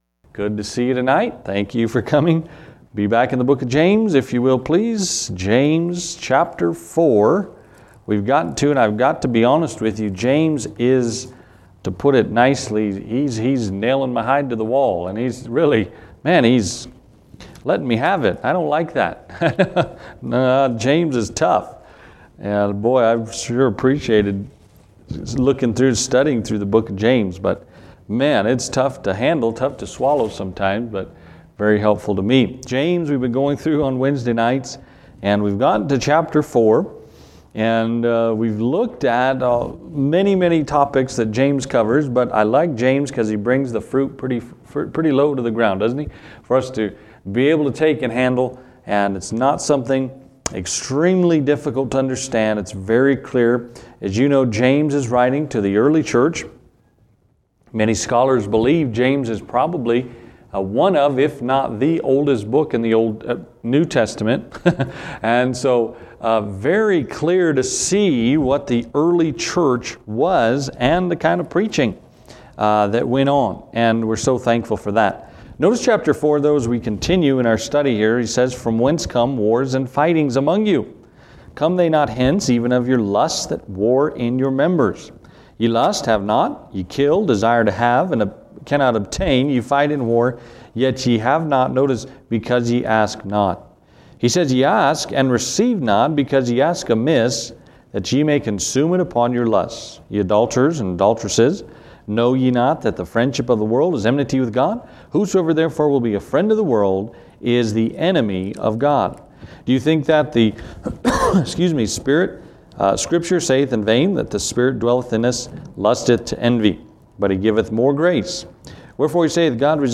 How then can we end wars? You’ll want to watch or listen to this sermon!